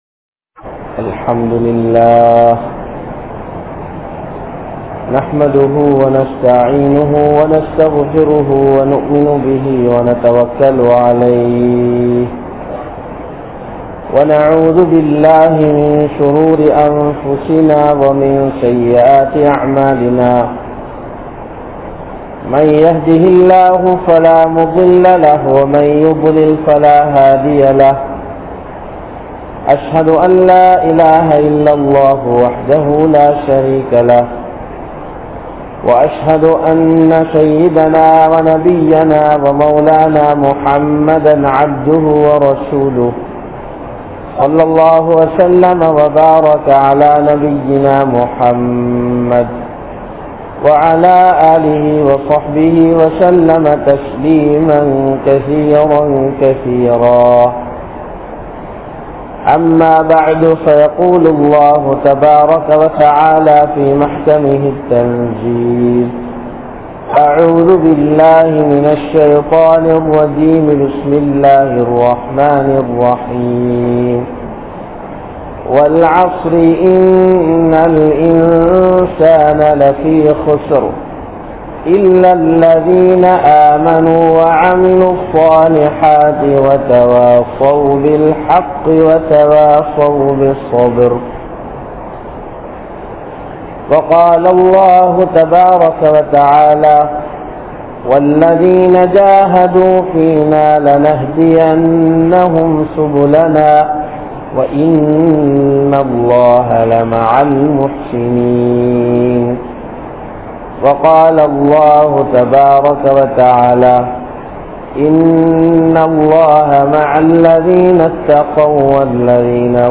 Unmaiyaana Arivin Velippaadau Enna? (உண்மையான அறிவின் வெளிப்பாடு என்ன?) | Audio Bayans | All Ceylon Muslim Youth Community | Addalaichenai
Colombo, GrandPass Markaz